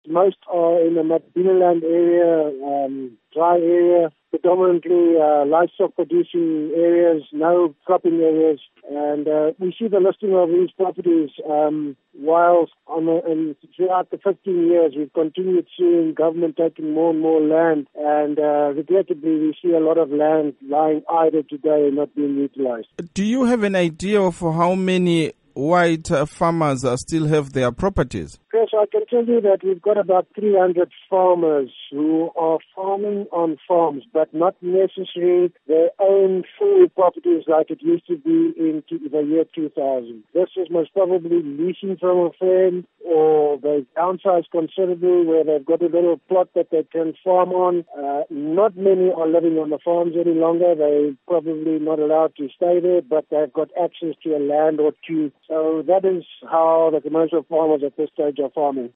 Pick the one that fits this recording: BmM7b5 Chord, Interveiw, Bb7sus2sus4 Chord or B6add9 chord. Interveiw